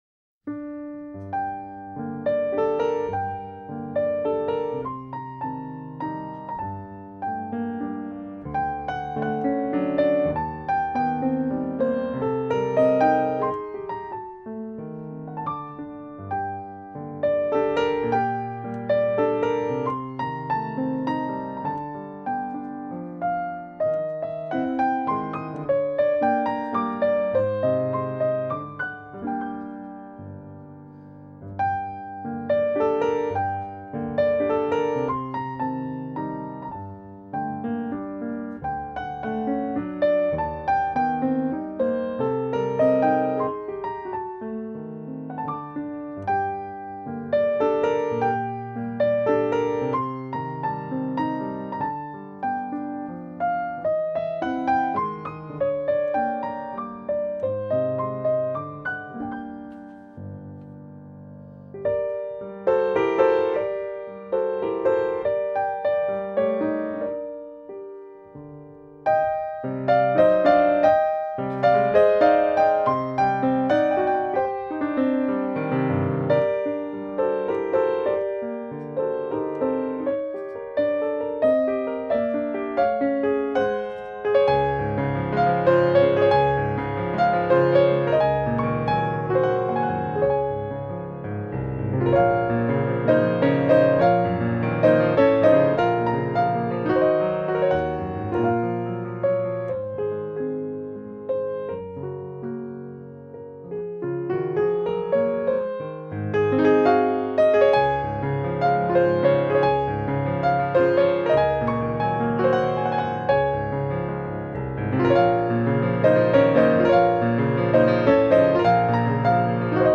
Banda sonora completa